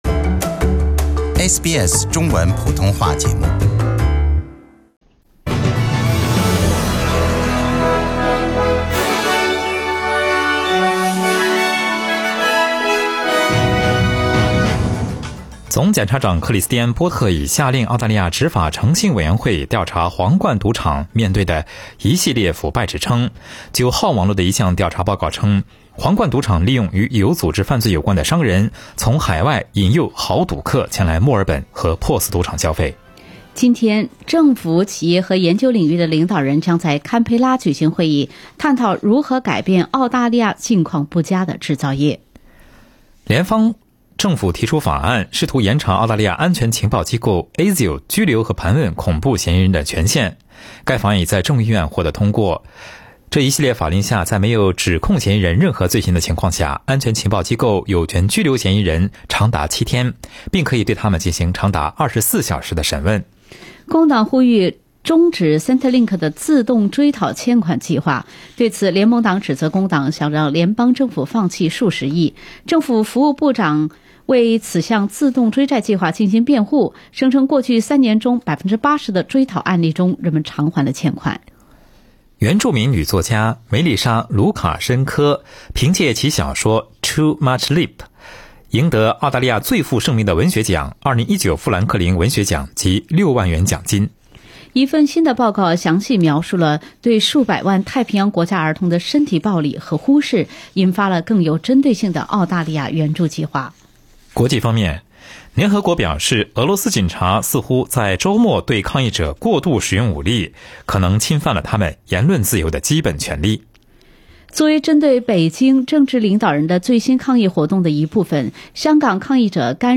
SBS早新闻（7月31日）